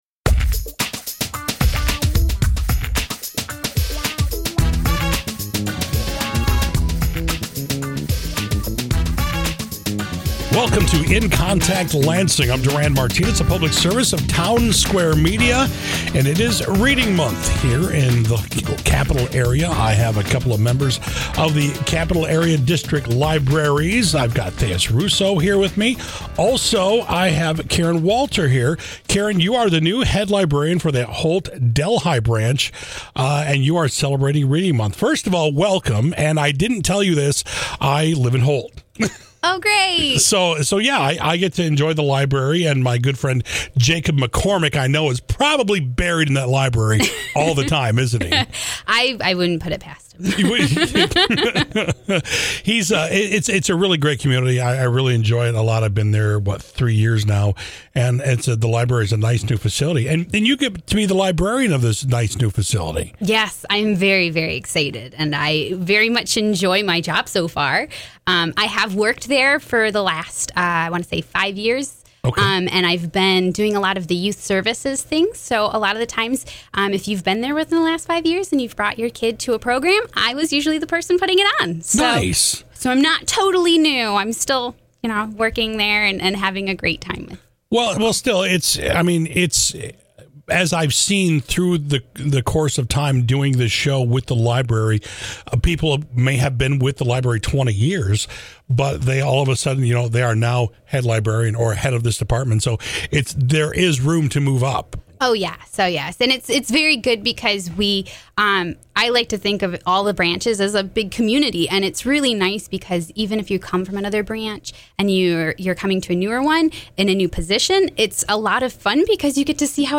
Townsquare Media In-Connect Interview (March 2019)